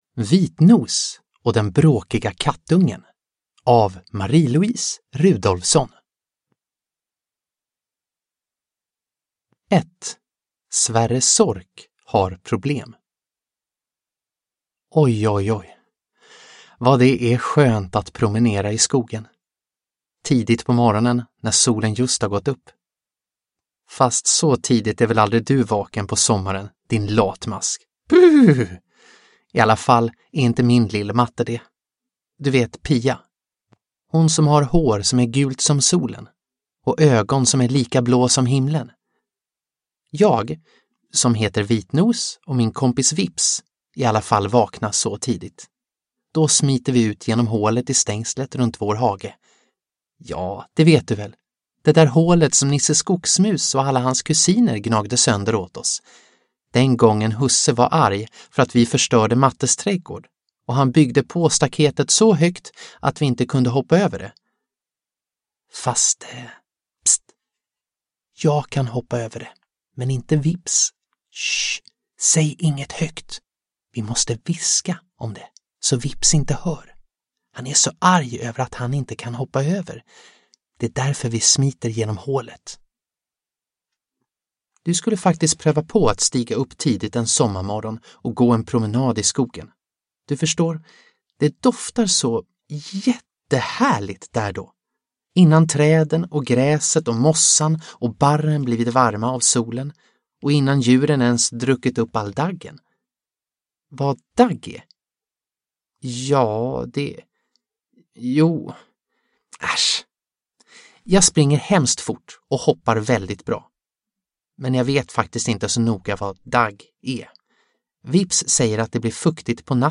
Vitnos och den bråkiga kattungen – Ljudbok – Laddas ner